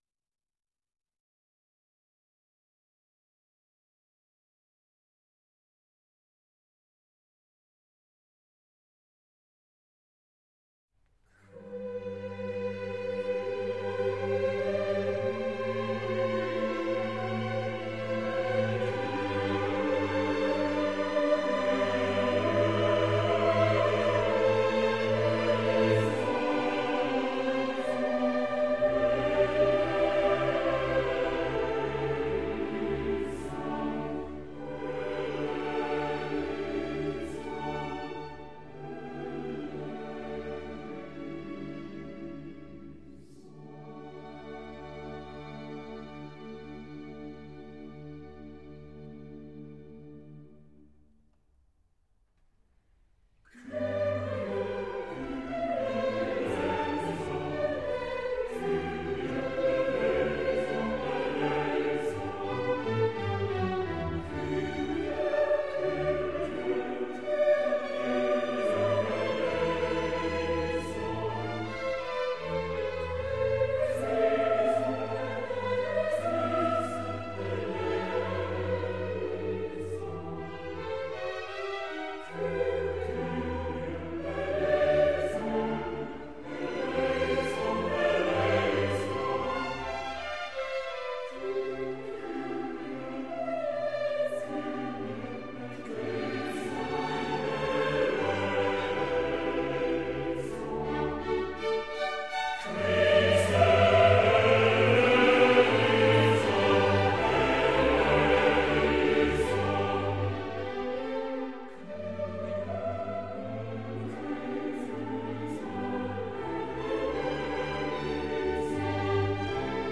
Mass
Group: Choir
Choral composition that sets the invariable portions of the Eucharistic liturgy.